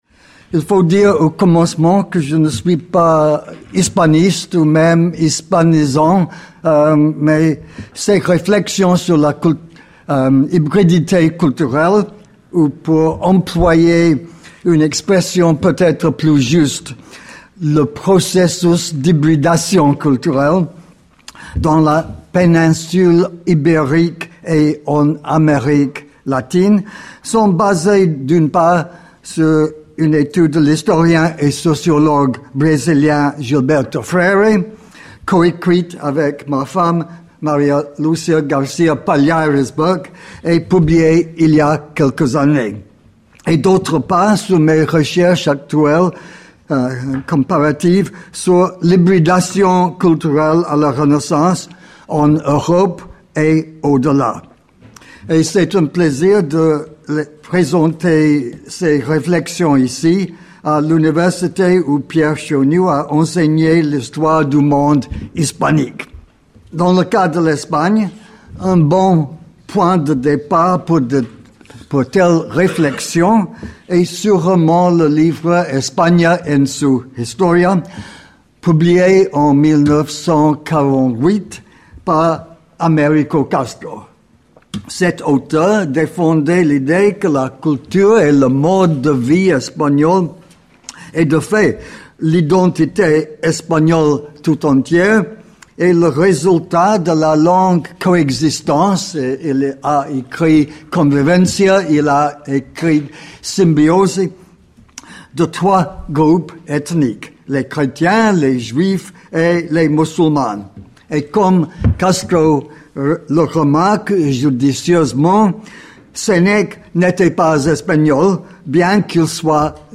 Le point de départ de cette conférence est l'interprétation de l'histoire espagnole comme l'interaction entre les trois cultures juive, chrétienne et musulmane. Les débats sur cette interaction sont souvent centrés sur le Moyen Âge, mais la Renaissance espagnole, comme les autres renaissances européennes, peut aussi être analysée sous cet angle.